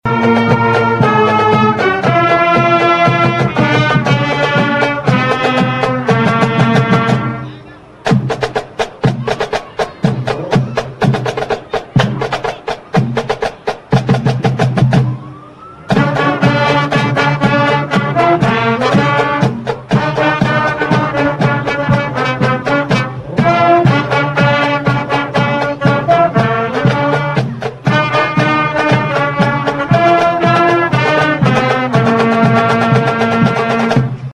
The parade was led by Cadiz Police Chief Tyler Thomas and parade Grand Marshall 56th Circuit Judge Jamus Redd who said he was honored to be chosen this year.